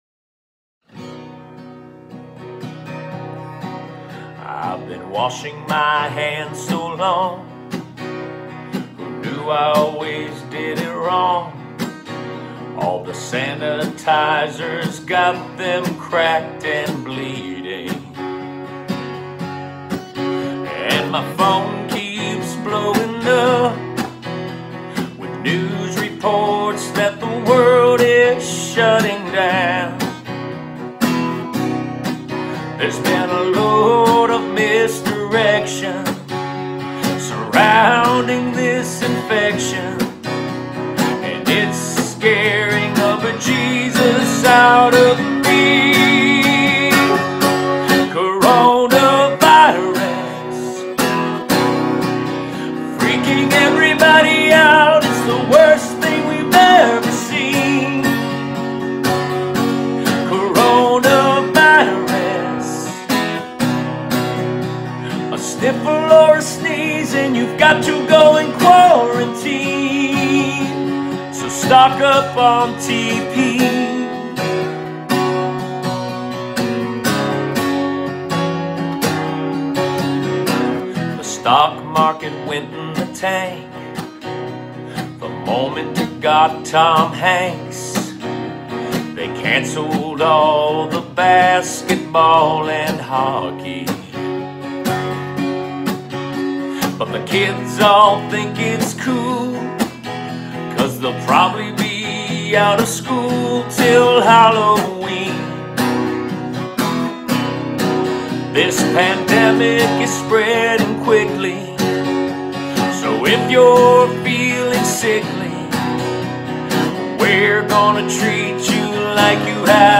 5) A SECOND PARODY SONG